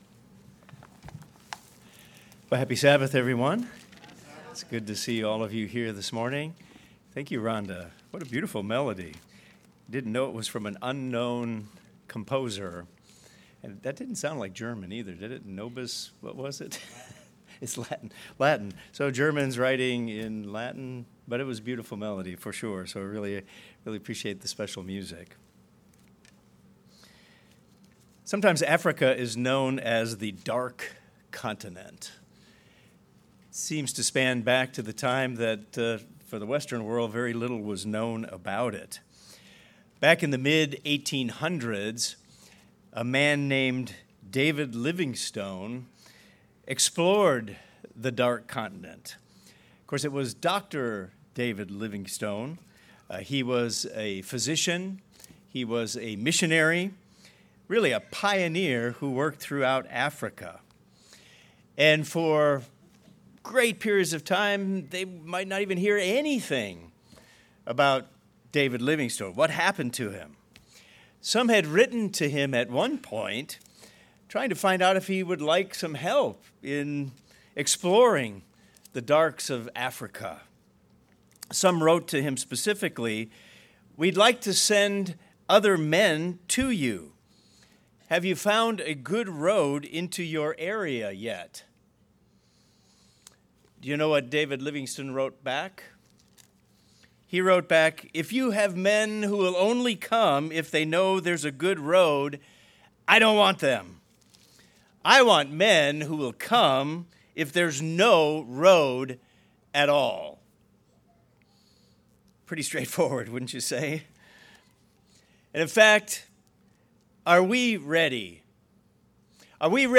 Where does advancing the mission to preach the gospel begin? This sermon addresses a key factor for us all in order to do our share in the Work of God.